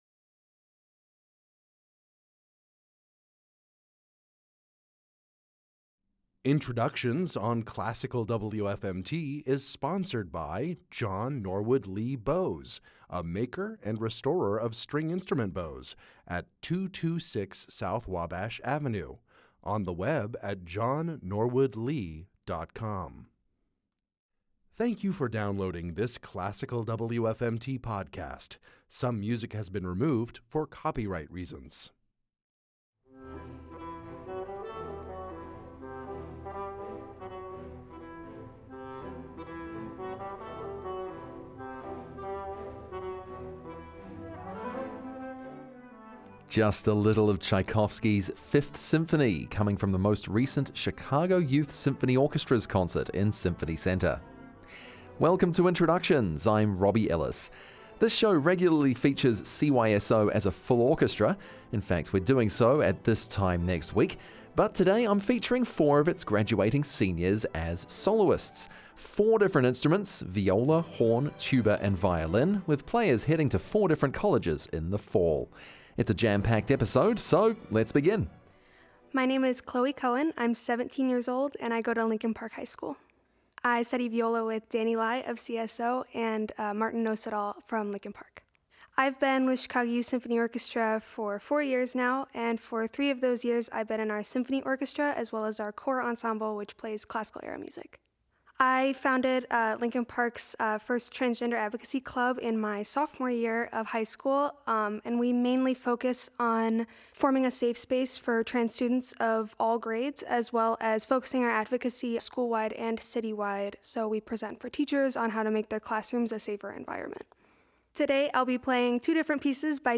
Introductions is WFMTs weekly Saturday morning program that features talented pre-college classical musicians in the Chicago area. Soloists and chamber ensembles perform live in WFMTs Levin Performance Studio. Large ensembles, including orchestras, choirs, and bands, are recorded at their home venues and broadcast at later dates.